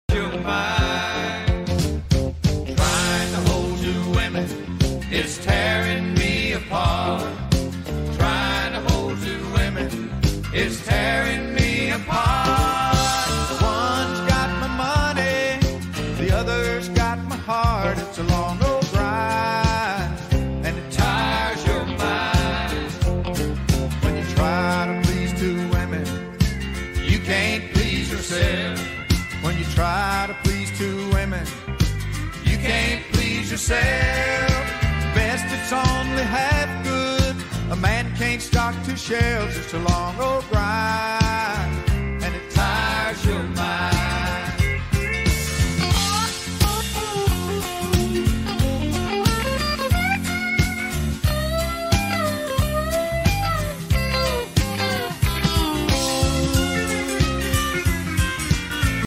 🚫🛥 More country twang than marina swing…